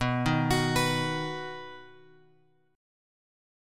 B chord {7 6 x x 7 7} chord
B-Major-B-7,6,x,x,7,7-8.m4a